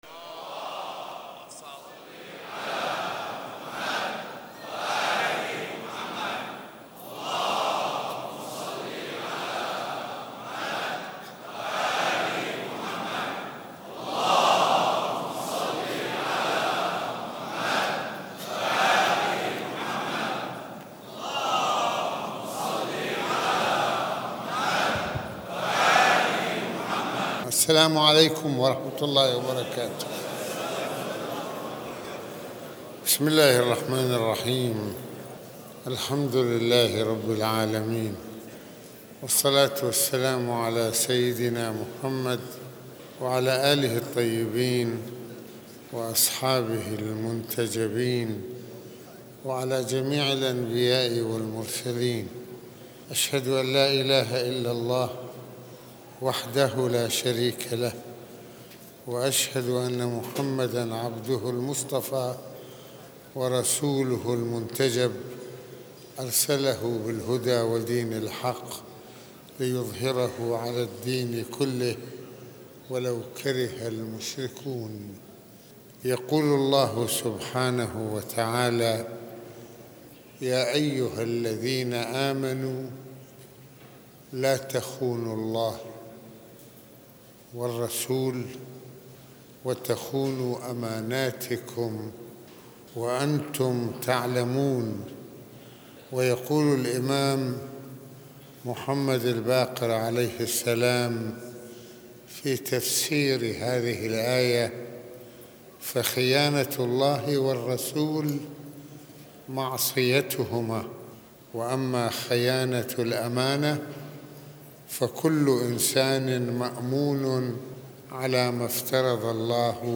المناسبة : خطبة الجمعة المكان : مسجد الإمامين الحسنين (ع)